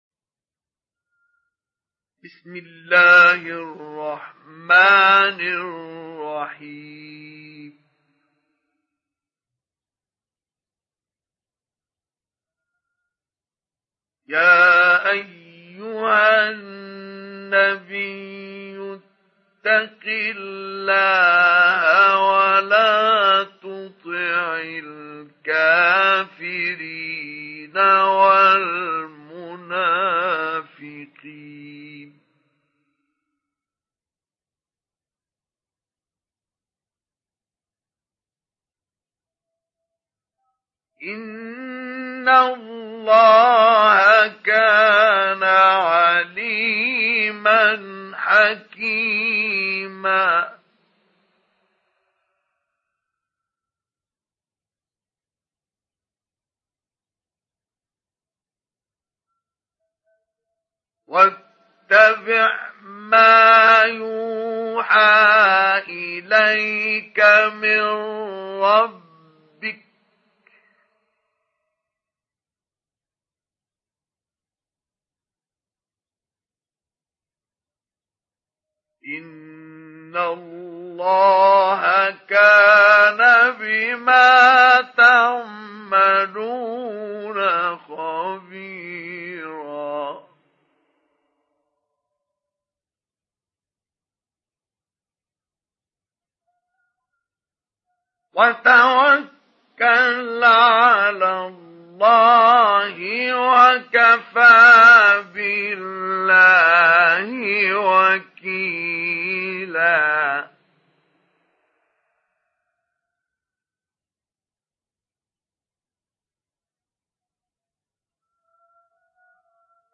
Surat Al Ahzab Download mp3 Mustafa Ismail Mujawwad Riwayat Hafs dari Asim, Download Quran dan mendengarkan mp3 tautan langsung penuh
Download Surat Al Ahzab Mustafa Ismail Mujawwad